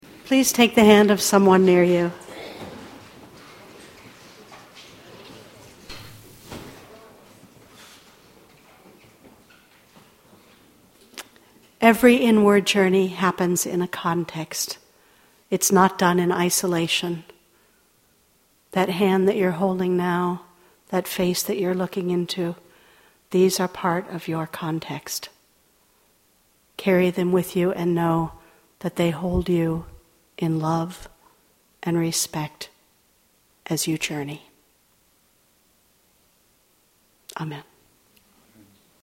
Audio File of Benediction
blessing2.mp3